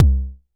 Index of /musicradar/essential-drumkit-samples/Vermona DRM1 Kit
Vermona Kick 03.wav